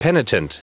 Prononciation du mot penitent en anglais (fichier audio)
Prononciation du mot : penitent